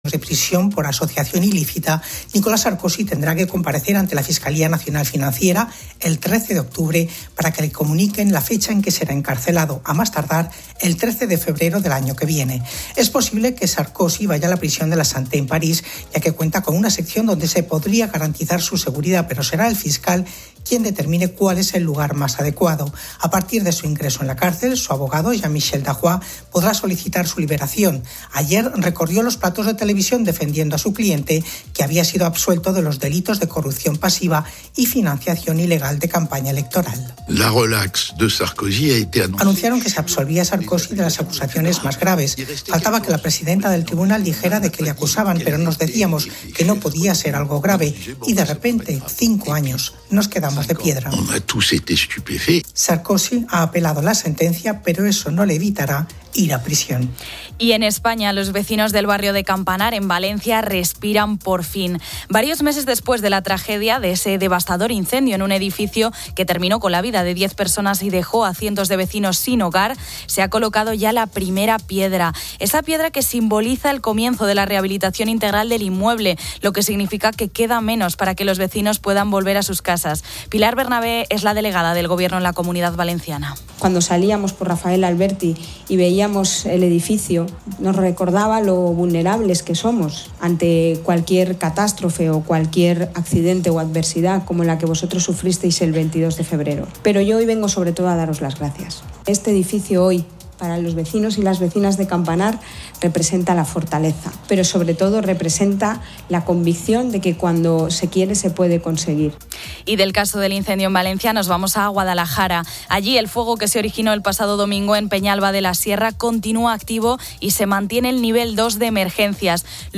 En "Nido de Ratas", Zapatero y Rajoy compiten en un "Pasapalabra", con Rajoy acertando todas las respuestas a pesar de su "ritmo lento". Finalmente, se repite la famosa llamada de COPE a Annette Bening en 2004, donde la despiertan en su hotel de San Sebastián para preguntarle por su desayuno.